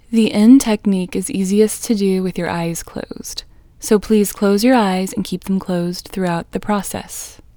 IN Technique First Way – Female English 1